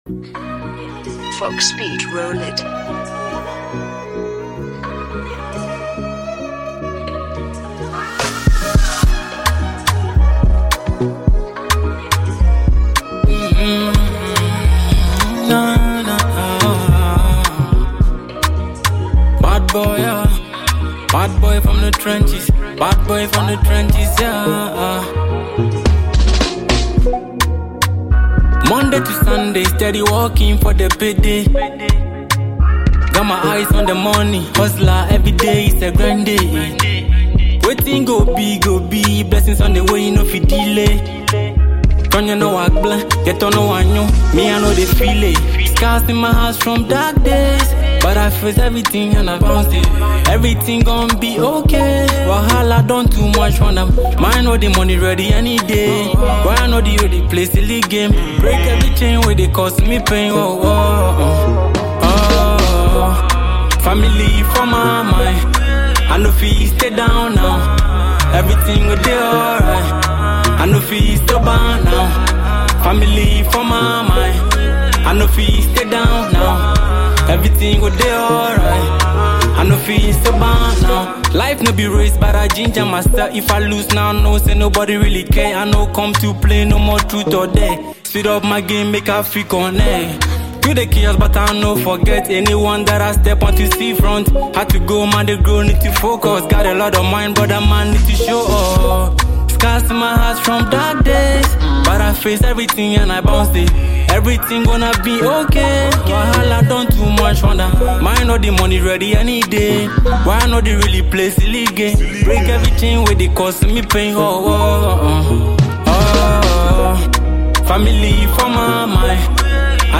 a blend of mellow beats and driving rhythms